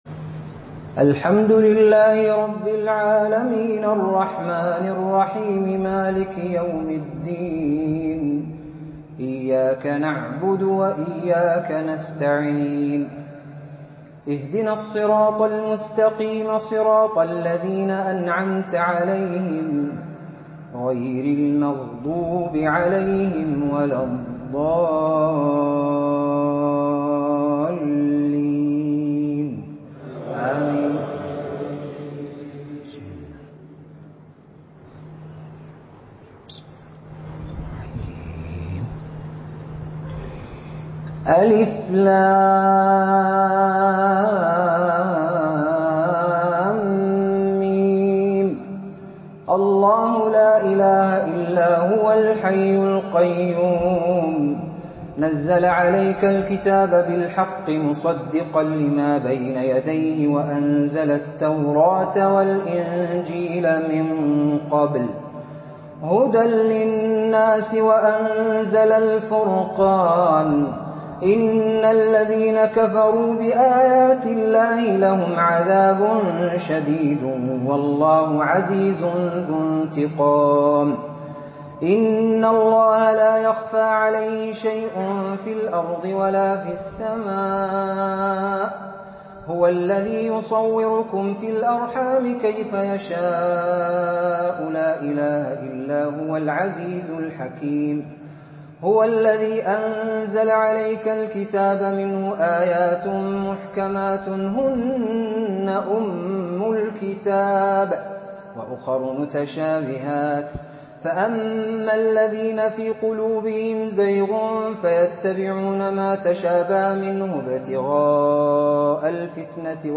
تلاوة إبداعية بالبيات
من جامع الصفا ببيشة .تراويح ١٤٤٤